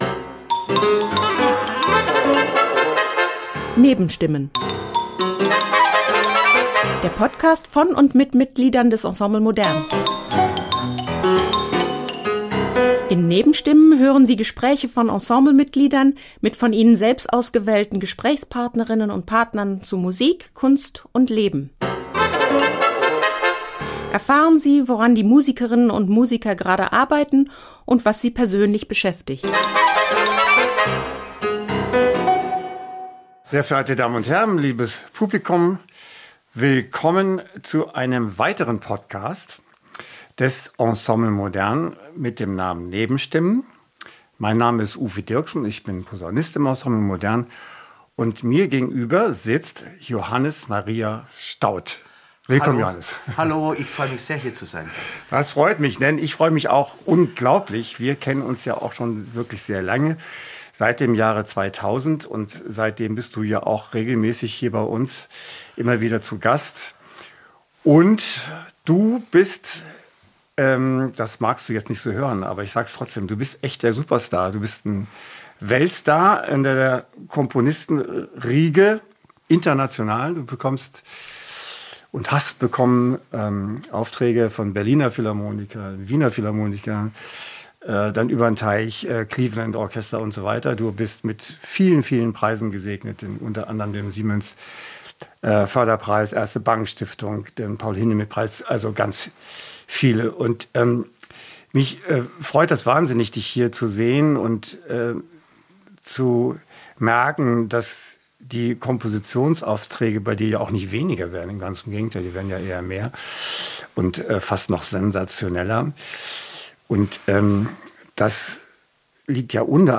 Der Podcast mit und von Mitgliedern des Ensemble Modern